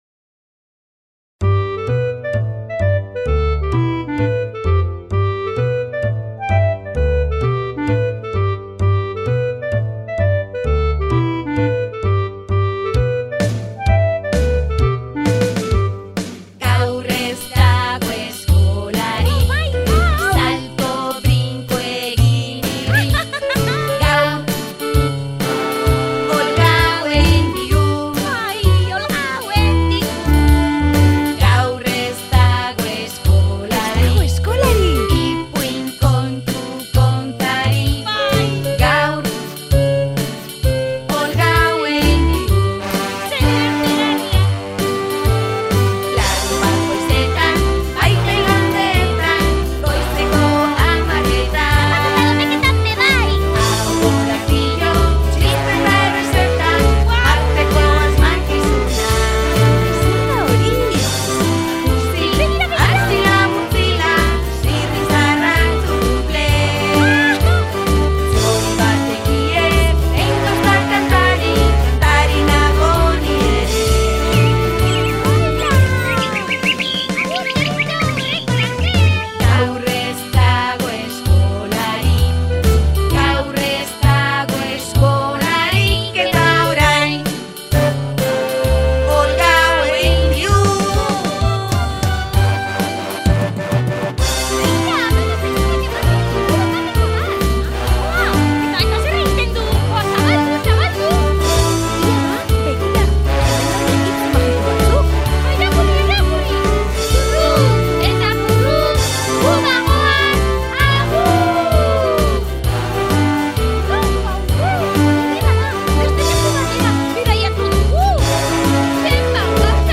Tartean, ahokorapiloak, asmakizunak, ipuinak eta kantak ere, entzun eta ikasi ahal izango ditugu.